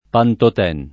[ pantot $ n ]